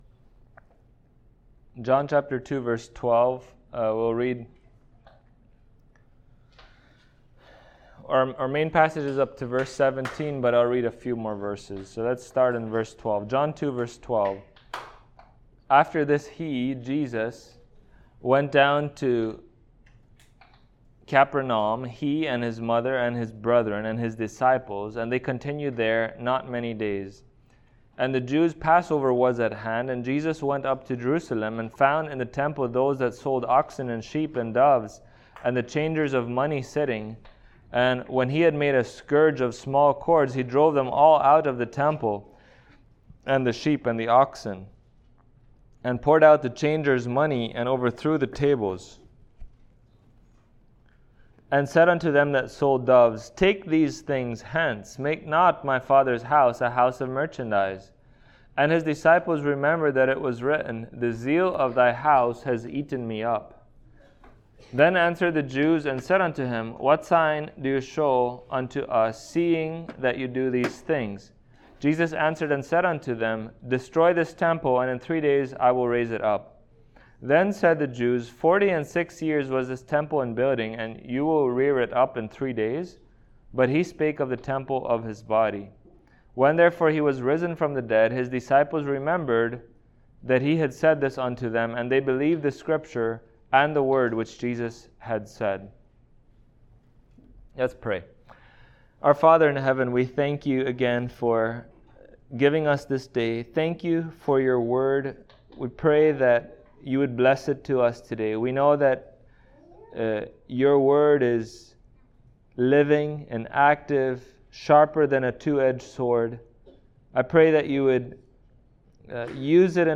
Passage: John 2:12-17 Service Type: Sunday Morning Topics